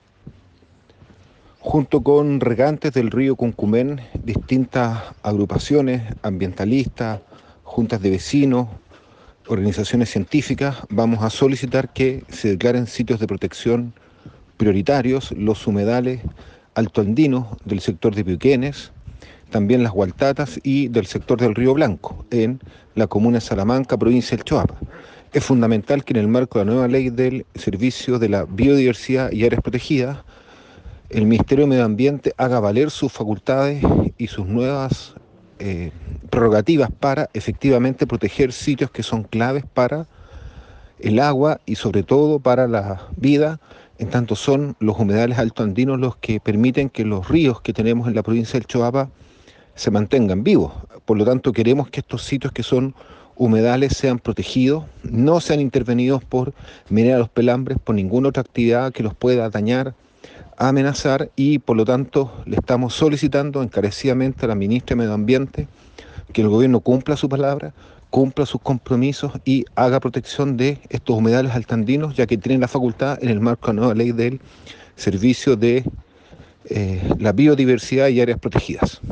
En el mismo sentido, el senador por la Región de Coquimbo Daniel Núñez, indicó que